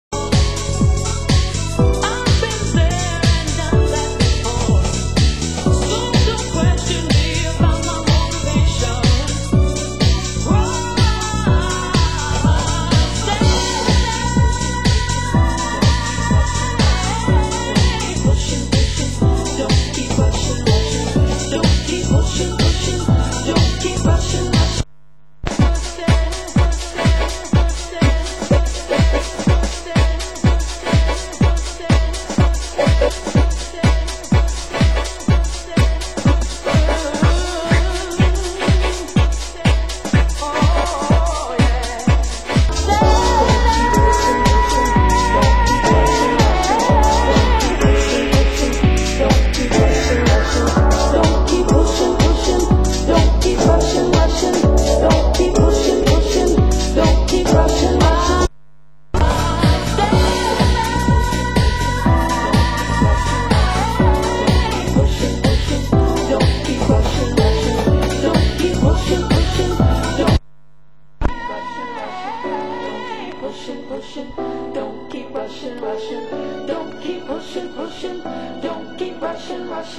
Format: Vinyl 12 Inch
Genre: UK House